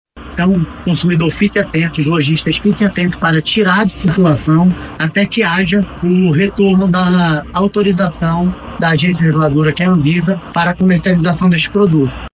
O diretor-presidente do Procon Amazonas, Jalil Fraxe, alerta consumidores e lojistas quanto a medida.